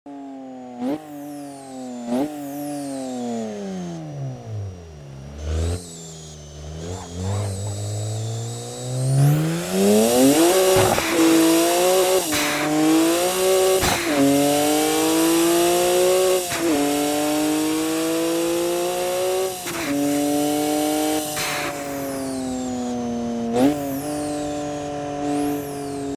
Bmw 335i Single Turbo Sounds sound effects free download